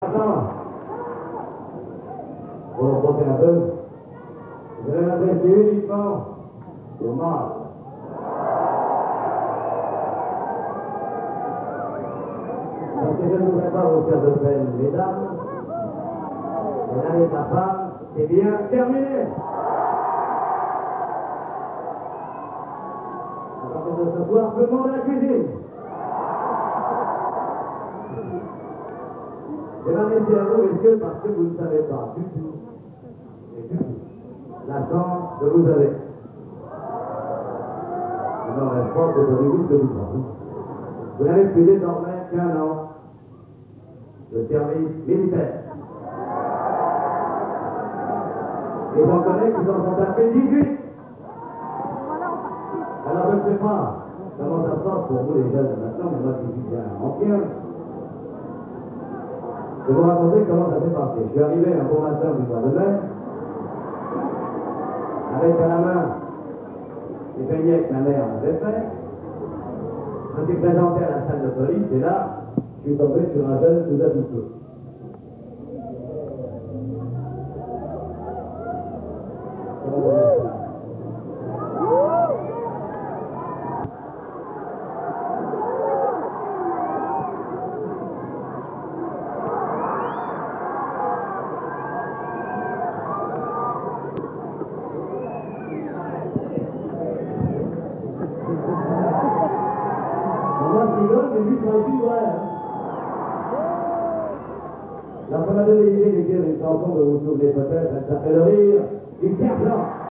Bootlegs (enregistrements en salle)
Les Lecques (18 juillet 1976)